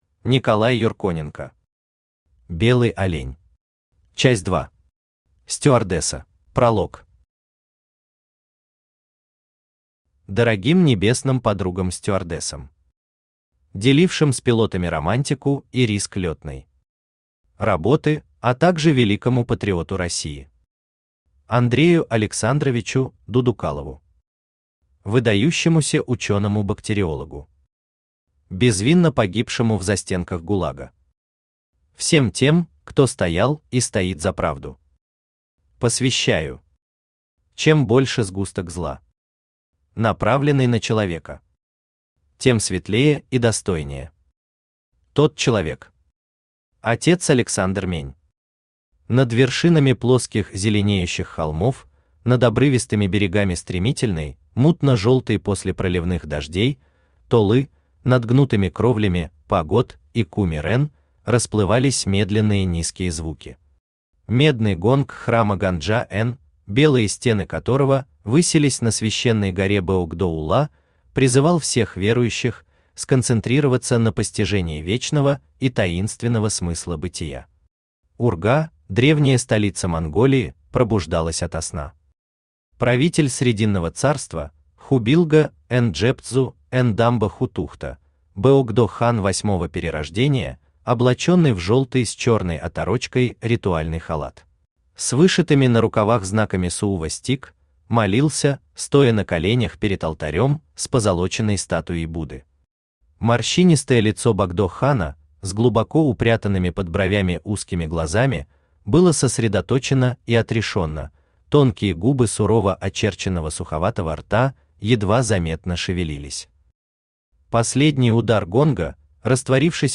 Аудиокнига Белый олень. Часть 2. Стюардесса | Библиотека аудиокниг
Стюардесса Автор Николай Александрович Юрконенко Читает аудиокнигу Авточтец ЛитРес.